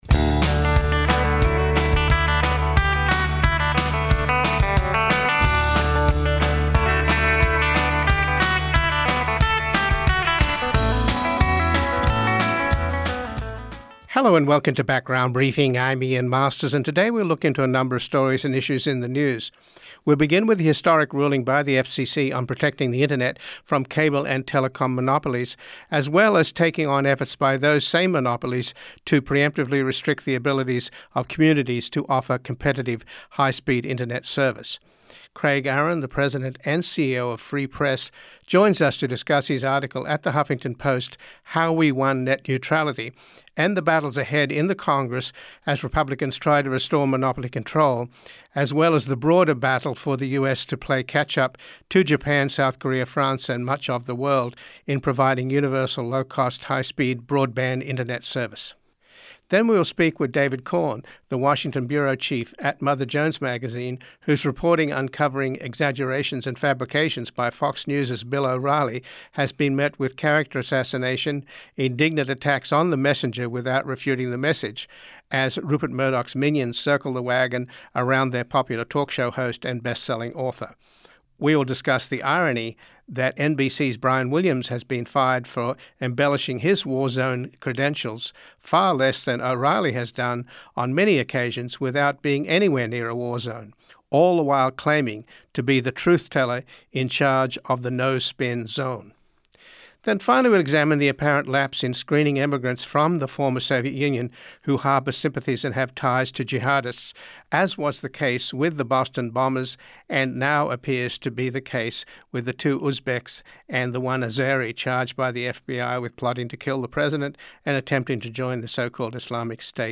Full Program LISTEN TO FULL PROGRAM Part 1 Today we broadcast from Capitol Hill, just across the street from the Supreme Court where people are gathering for tomorrow’s very important hearings on the Affordable Care Act which went through a near death experience with Chief Justice John Roberts saving it.